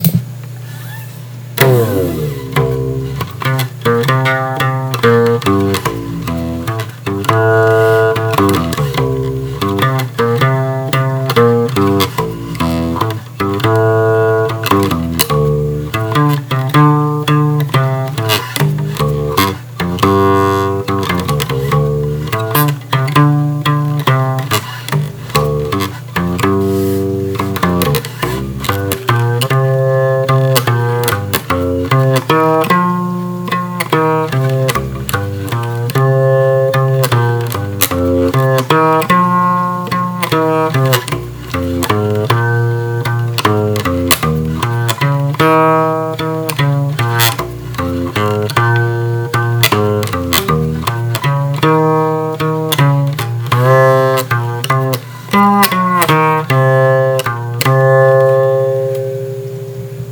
Zvuk je opravdu hutný a i díky piezo snímači dostává akustický, až kontrabasový nádech. Ostatně posuďte sami z nahrávek, které jsou pořízené přes zvukovku do PC, bez úprav. První narávka je „na sucho“, bez zapojení, snímáno kondenzátorovým mikrofonem, který je hodně citlivý, takže je to spíš pro ilustraci.
Máš moc velkou citlivost na vstupu, takže je signál ořezaný, zkreslený a proto to ve zvuku chrčí.